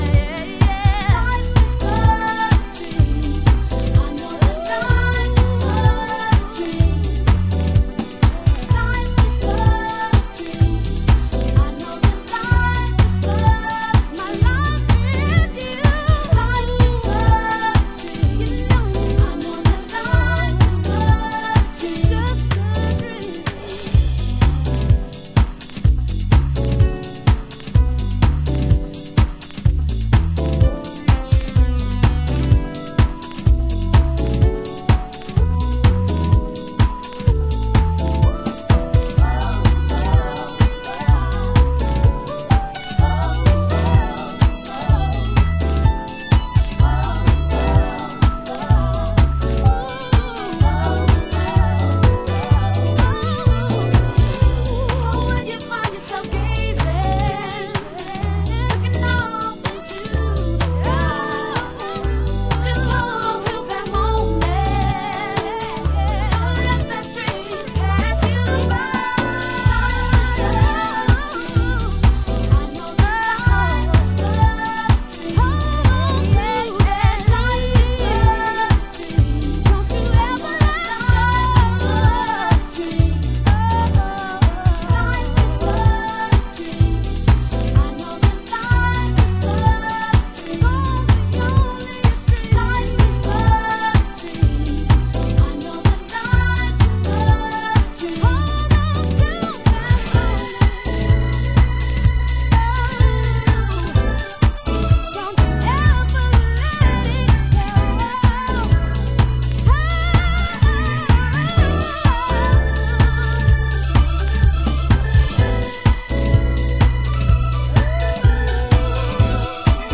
HOUSE MUSIC
(Ricanstruction Vocal)